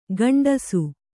♪ gaṇḍasu